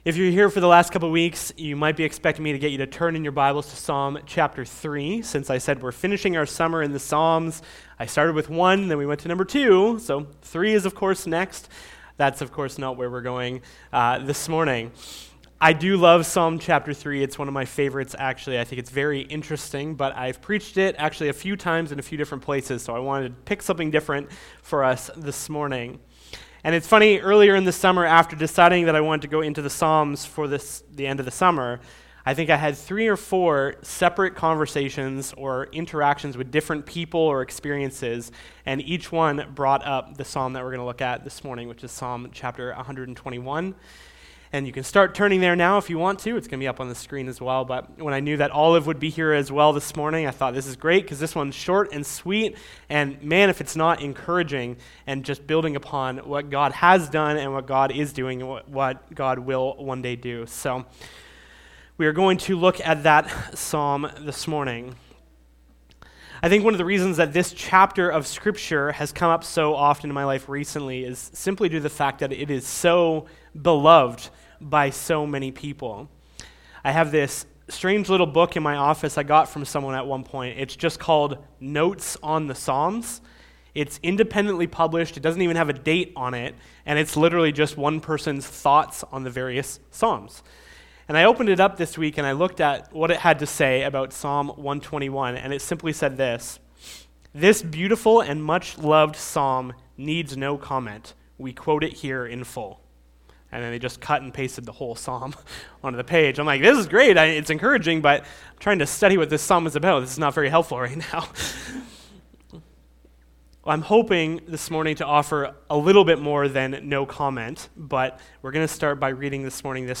Sermon Audio and Video From Where Will My Help Come?